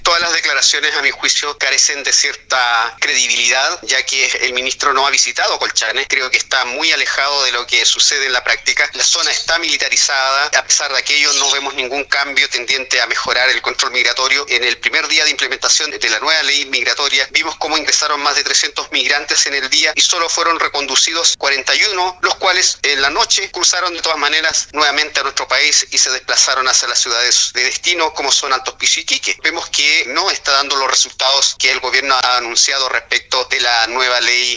En conversación con Radio Sago, Javier García señaló que las políticas migratorias son deficientes, además de que el gobierno se ha enfocado comunicacionalmente en distorsionar la realidad de su comuna, dijo. García criticó que se mantenga cerrado el complejo fronterizo, ya que se pierde la posibilidad de, al menos, aumentar el control de una parte de quienes ingresan al país.